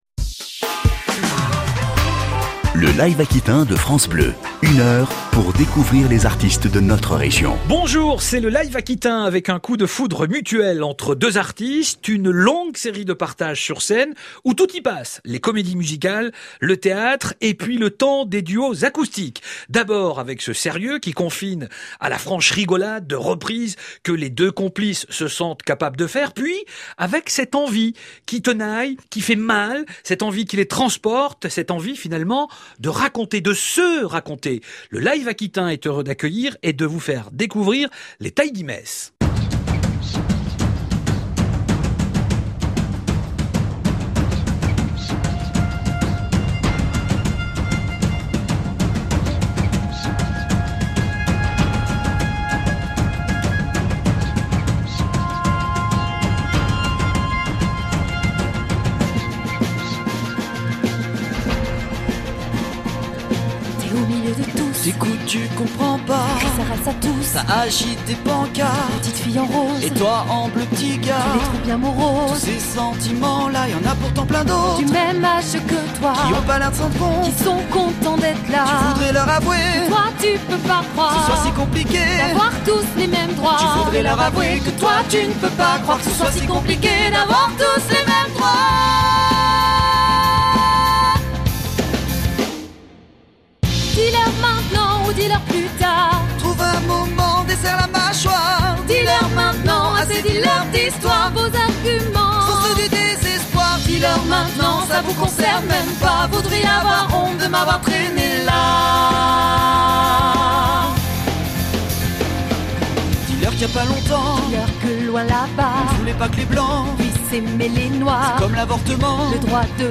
avec quelques titres en live + interview.